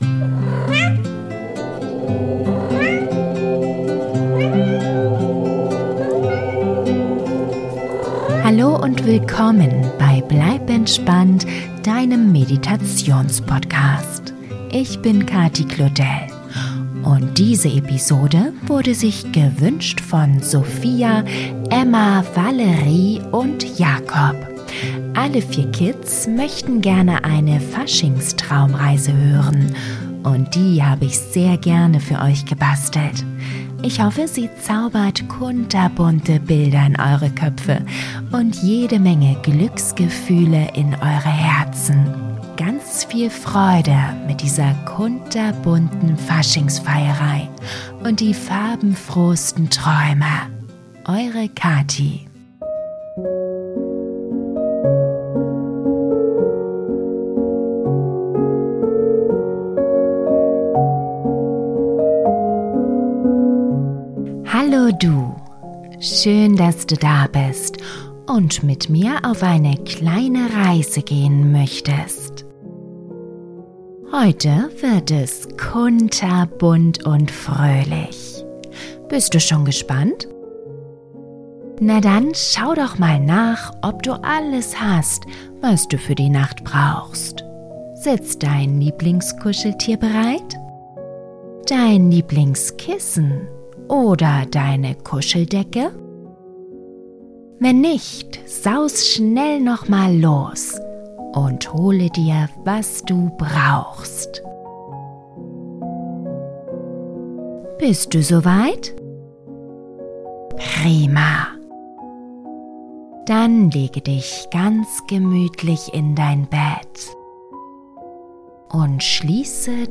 Traumreise für Kinder zum Einschlafen - Faschingsträume - Fasching Geschichte ~ Bleib entspannt!